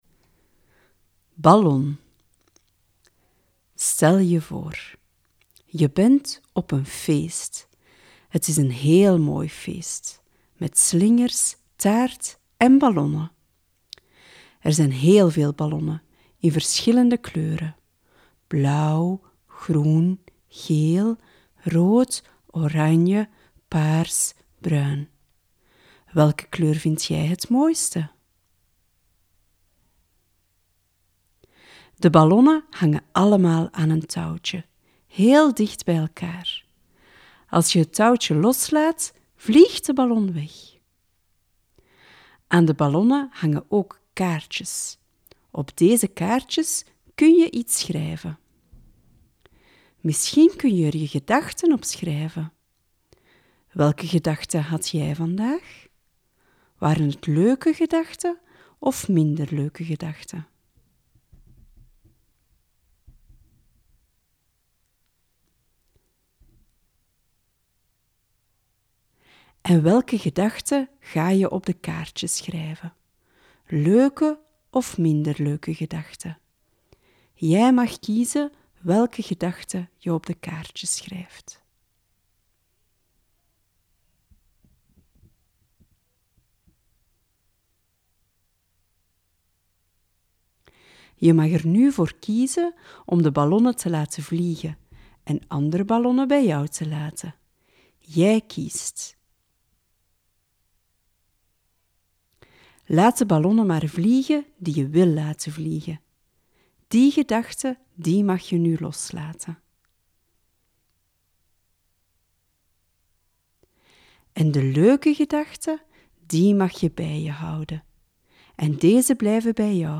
Een begeleide meditatie- of visualisatie-oefening kan je hierbij helpen.